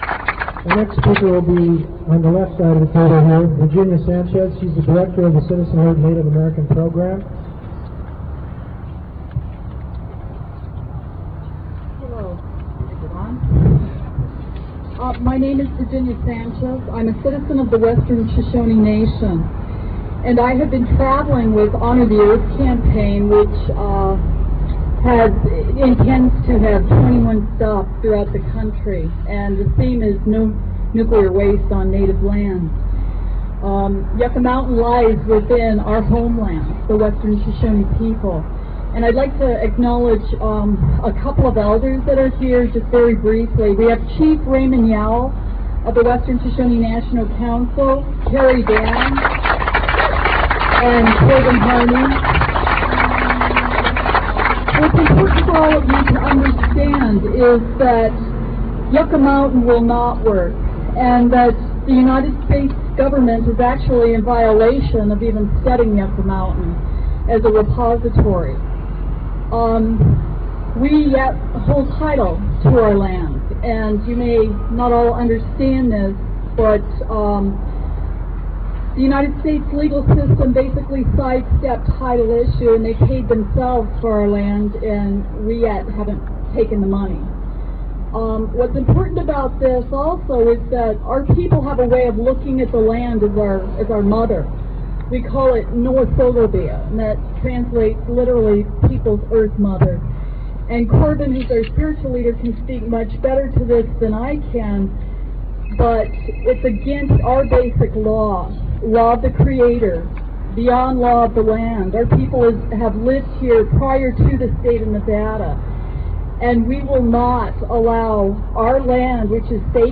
05. press conference